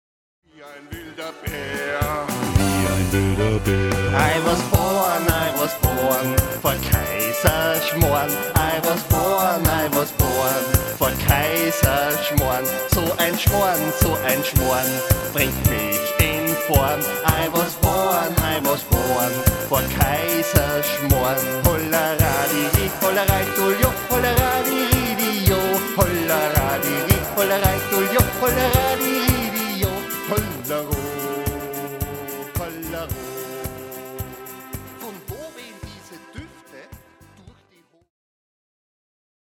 Cooking Fever Sound.